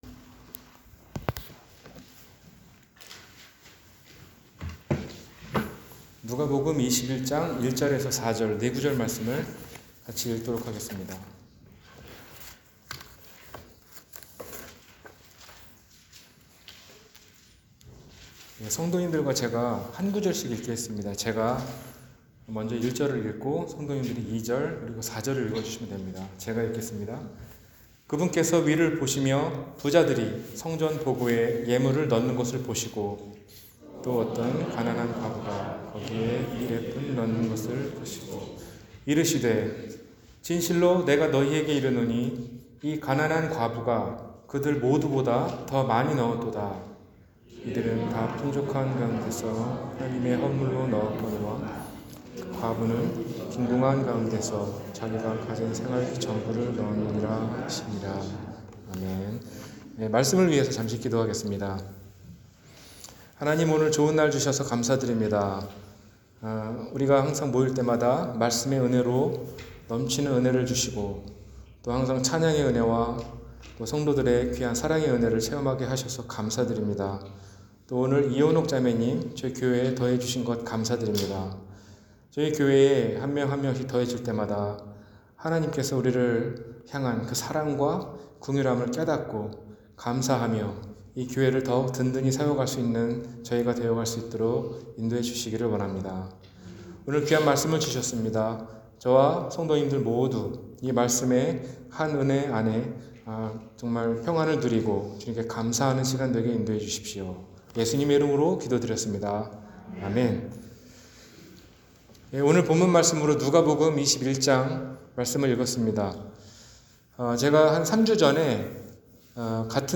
과부의 헌물-주일설교